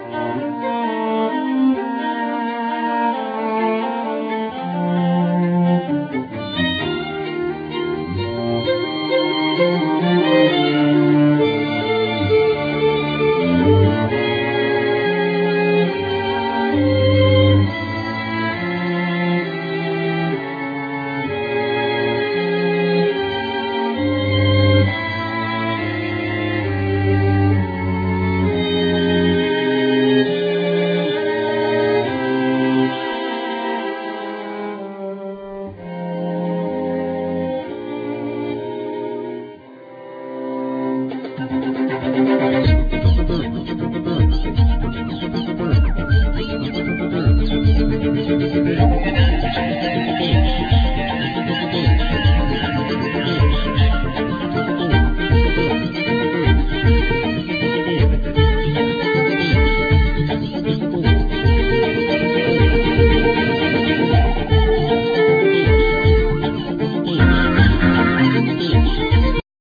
Guitar, Effect, Mix
Vocal, Piano, Programming
1st violin
2nd violin
Viola
Cello